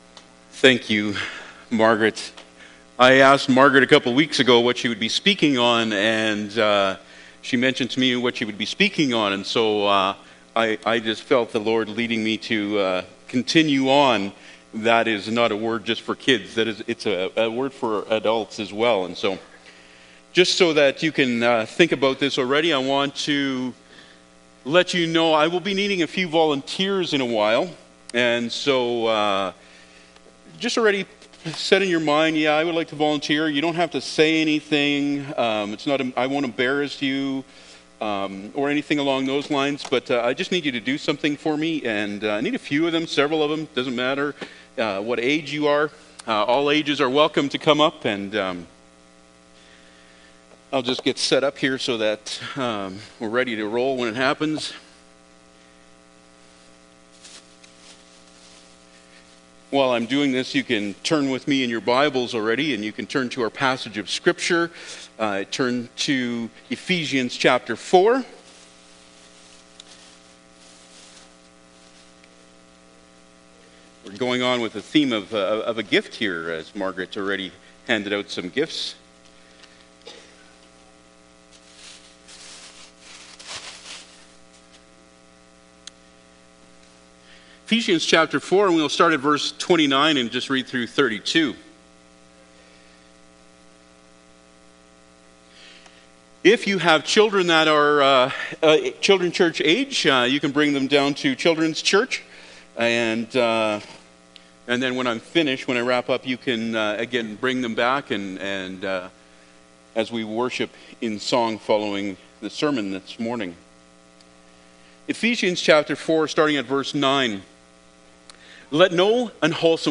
Eph 4:29-32 Service Type: Sunday Morning Bible Text